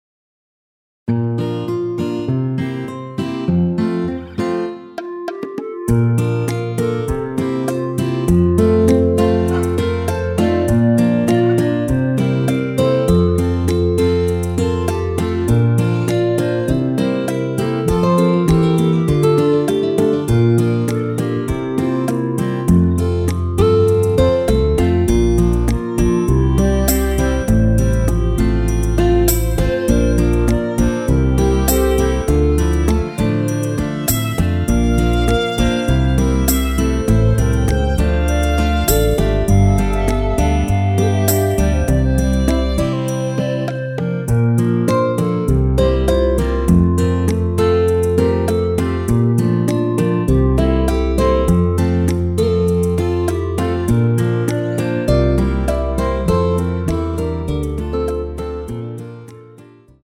시작 부분 여자 파트 삭제, 바로 남자 파트로 시작 됩니다.(가사 참조)
원키에서(-1)내린 멜로디 포함된 시작 부분 여자파트 삭제 제작된 MR입니다.(미리듣기 참조)
◈ 곡명 옆 (-1)은 반음 내림, (+1)은 반음 올림 입니다.
앞부분30초, 뒷부분30초씩 편집해서 올려 드리고 있습니다.